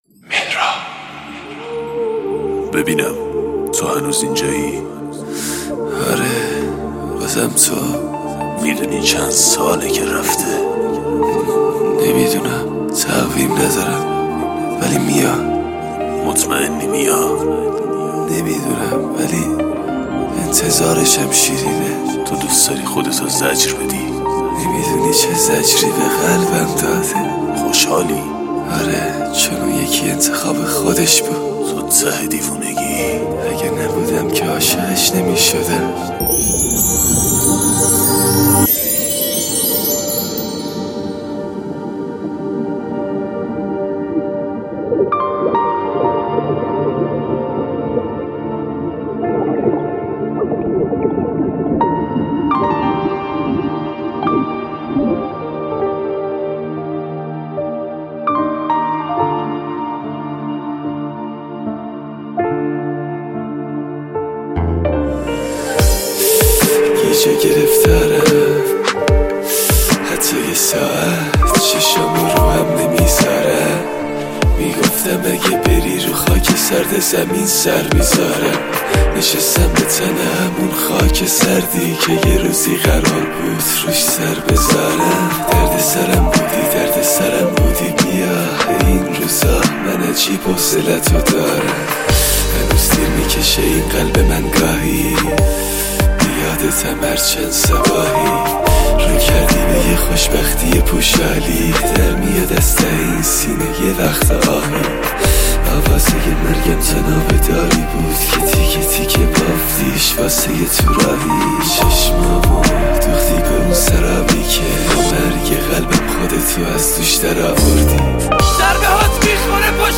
دیس لاو